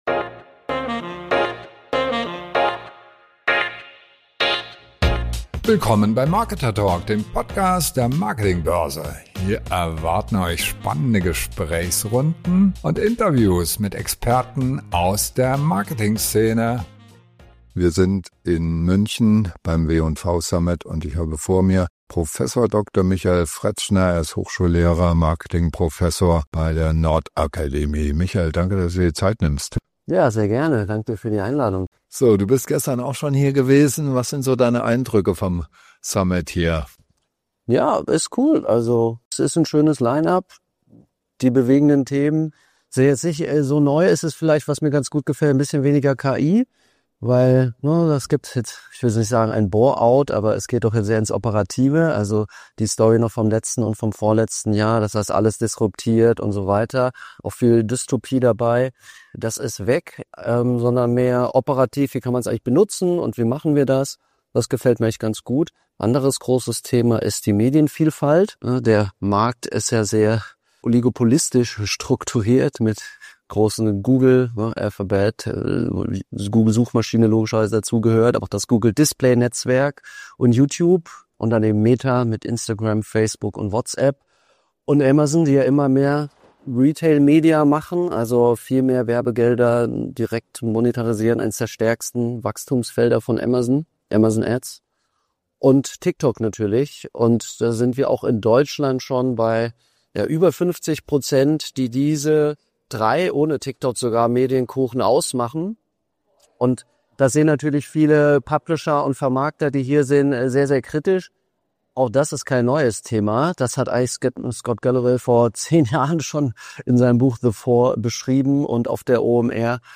Warum kontrollieren drei Konzerne über 50 Prozent des deutschen Medienkuchens? Ein Professor erklärt, wie die großen Tech-Giganten den Medienmarkt transformieren und was Marketing-Manager jetzt dagegen tun können.